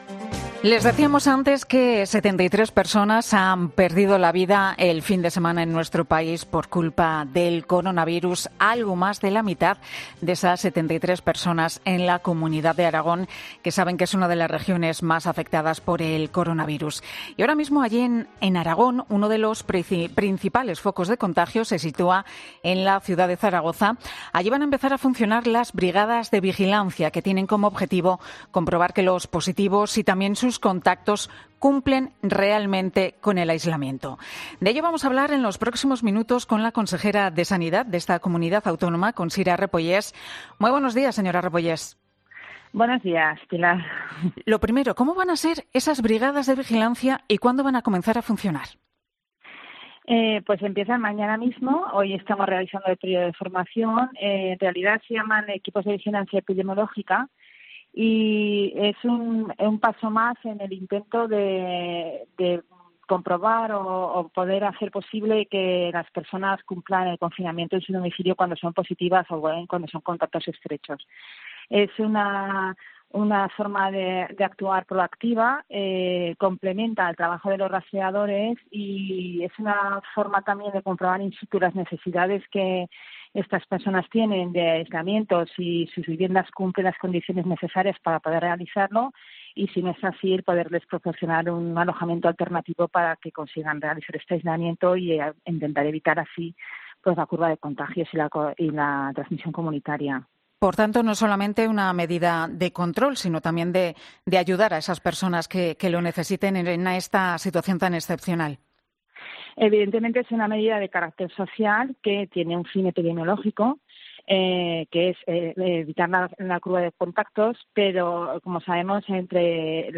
Esta mañana en 'Herrera en COPE' hemos hablado con Sira Repollés sobre la situación epidemiológica de Aragón y las nuevas medidas de control, como...